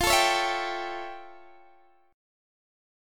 F+M9 Chord
Listen to F+M9 strummed